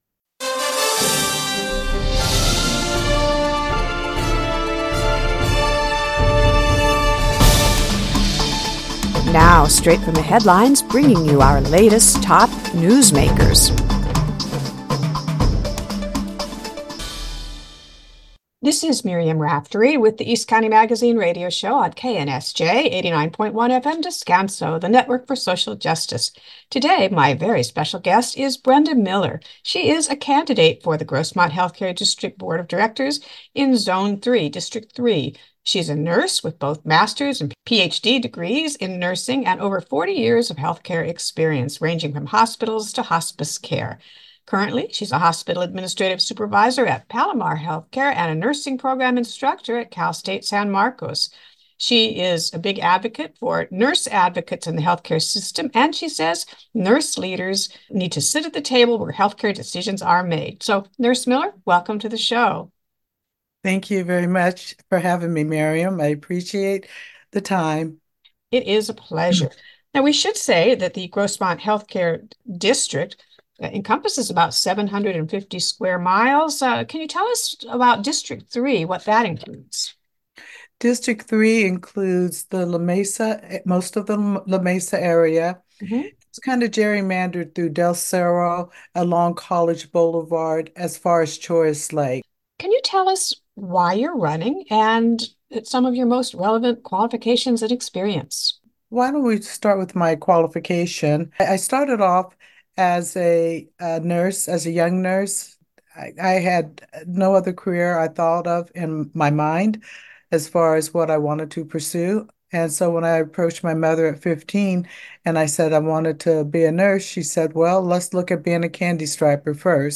Scroll down for highlights, or click the audio link to hear the complete interview.